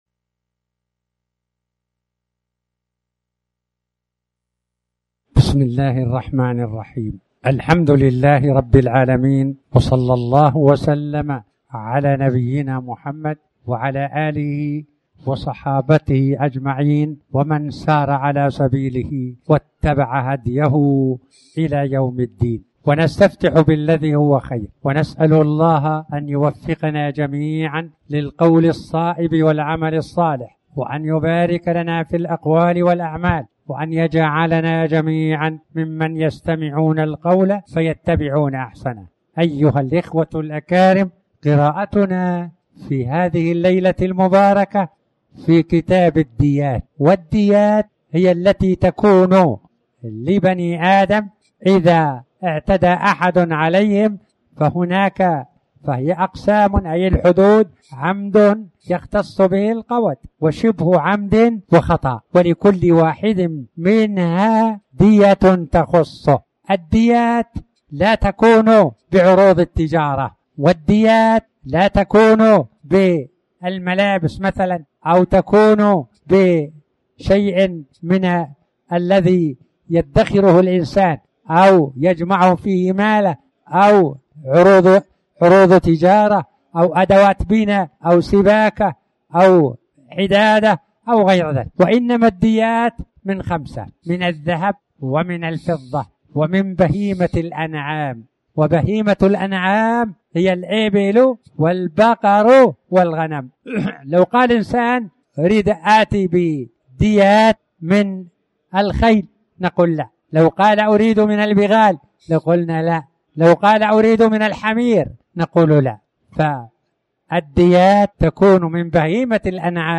تاريخ النشر ٢٠ شعبان ١٤٣٩ هـ المكان: المسجد الحرام الشيخ